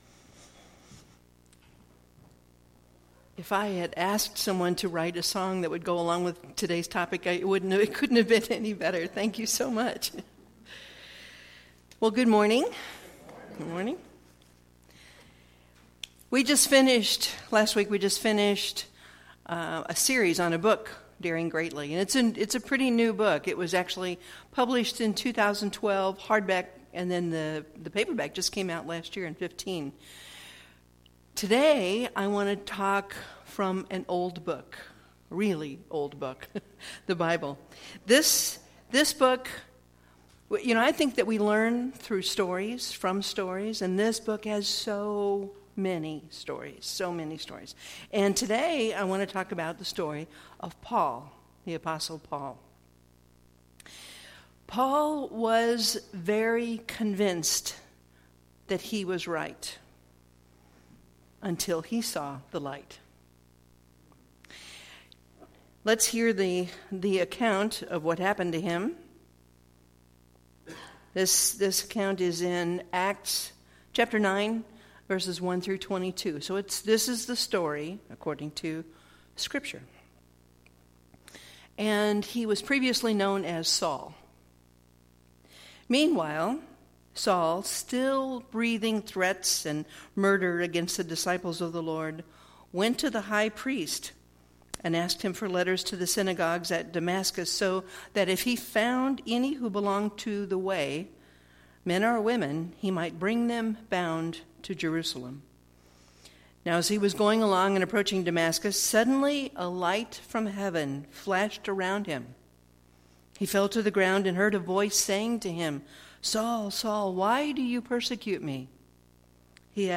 Series: Sermons 2016